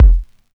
Kick (9).wav